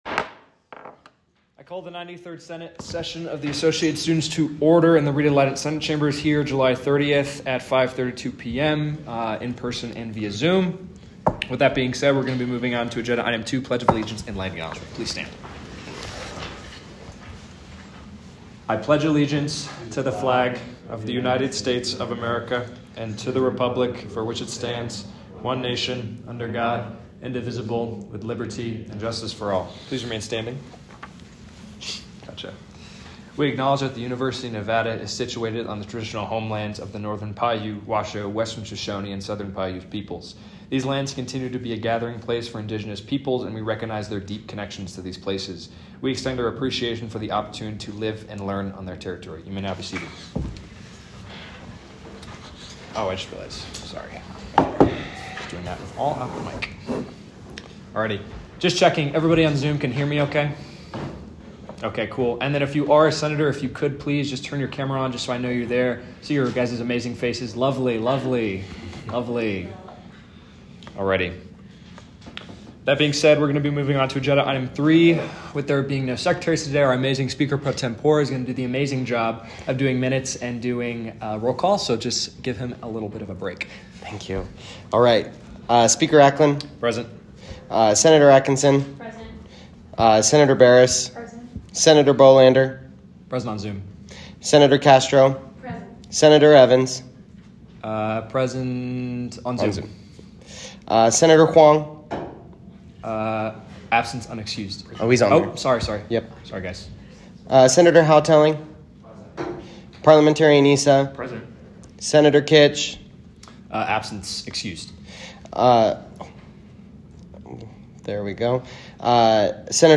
Meeting Type : Senate
Location : Rita Laden Senate Chambers